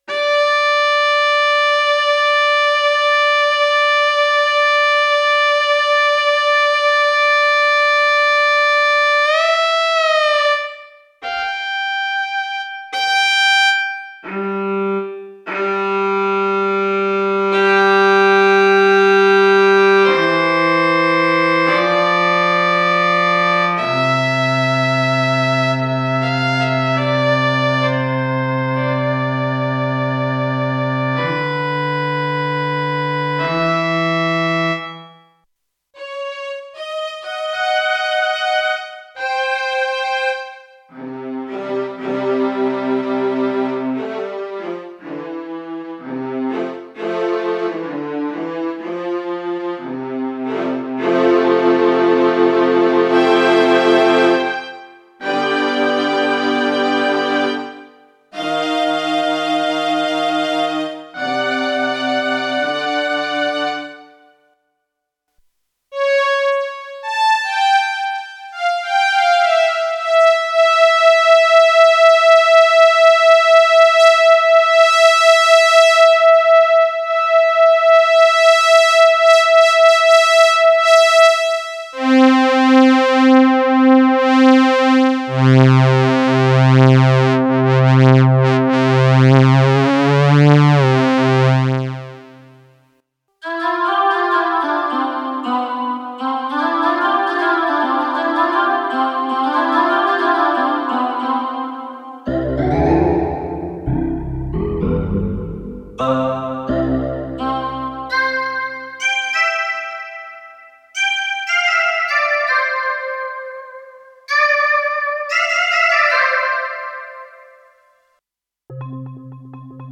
At any given time you will always just hear one patch of my SY-55 without any additional effect. The only treatment applied is normalization for each part.
Yamaha SY 55 Factory Patches Part4.mp3